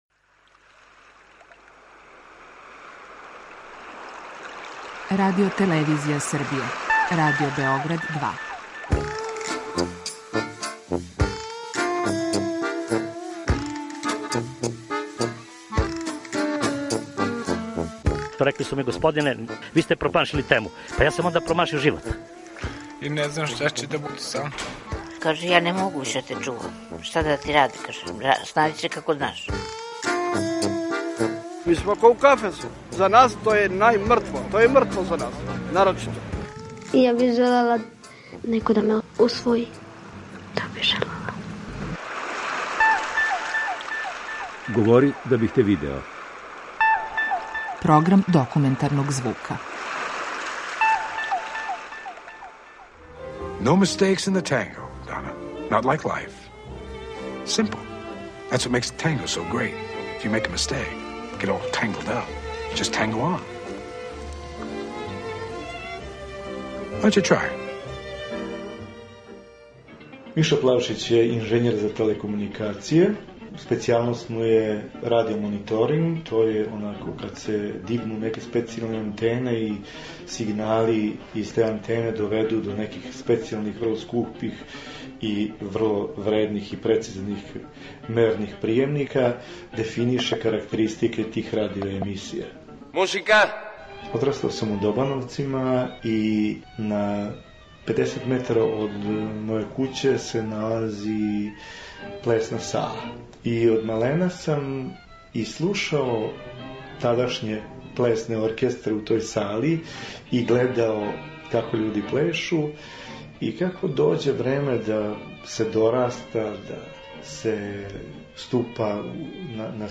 Документарни програм: Нежно путовање